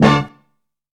THUNDER STAB.wav